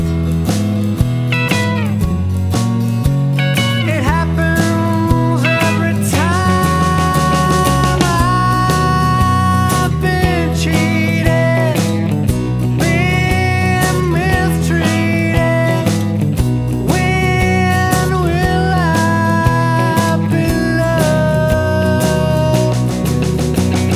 No Harmony Pop (1950s) 2:06 Buy £1.50